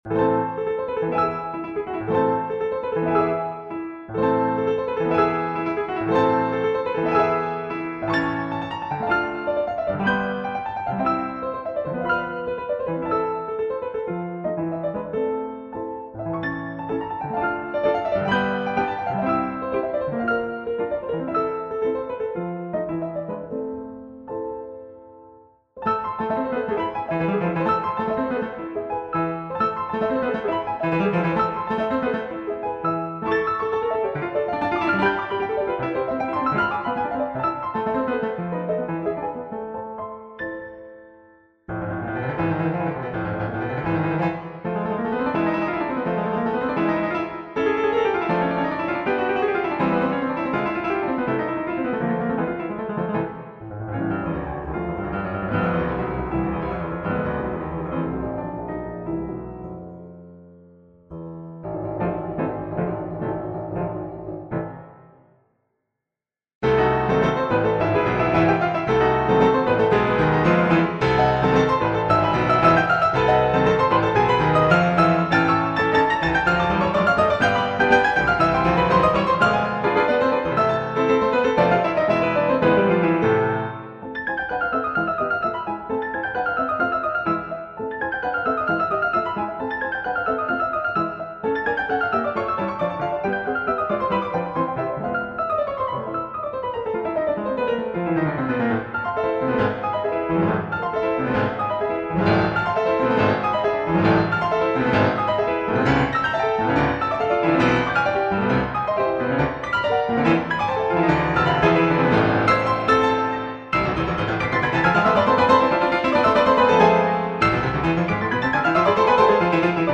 冒頭から出し惜しみなく壮大に駆け出し、煌びやかに華やかに旋律が飛び回ります。
６変奏目あたりからは、とにかく超絶技巧を楽しんでいるかのような煩雑で大忙しの展開です。
の上で、なんとコーダでサクッとシャープ３つのホ長調に転調し、派手に締めくくります。